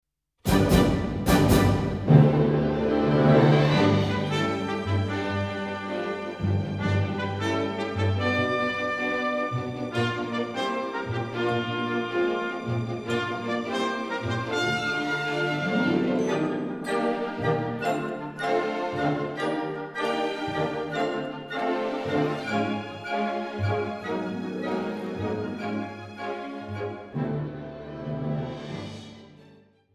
New Zealand Orchestral Music